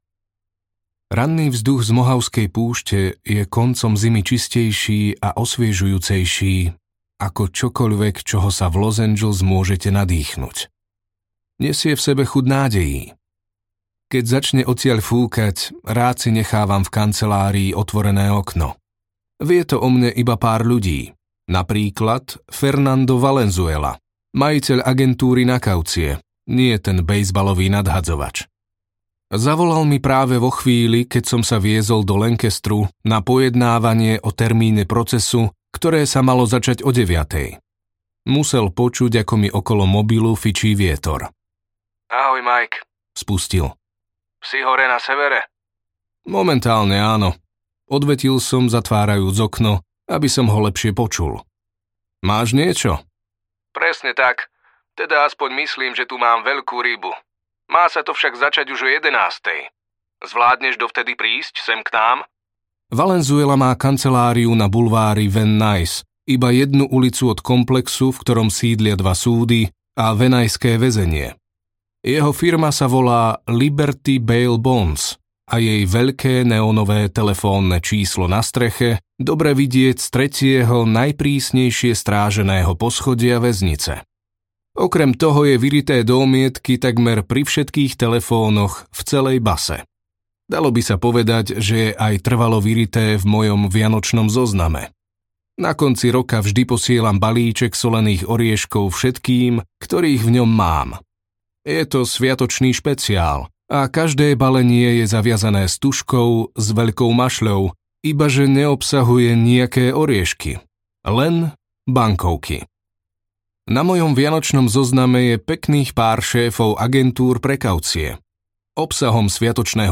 Advokát zo zadného sedadla audiokniha
Ukázka z knihy